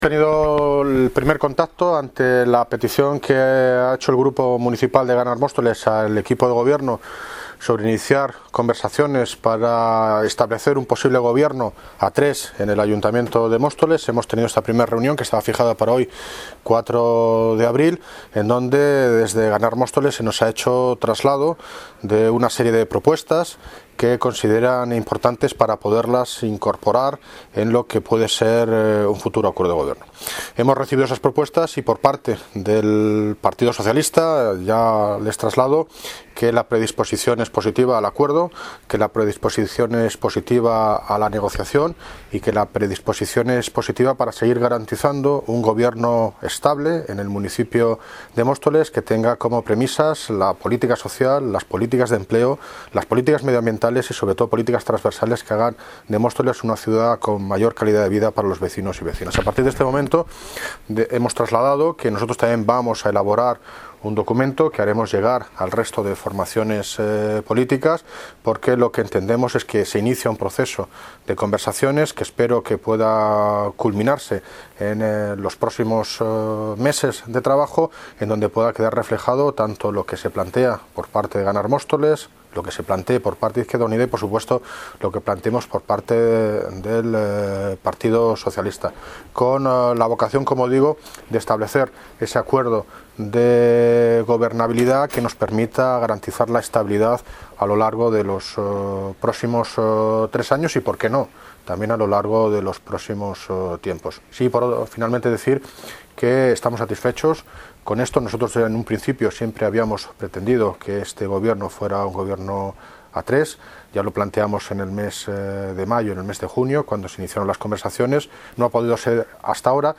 Audio - David Lucas (Alcalde de Móstoles) Sobre Gobierno a Tres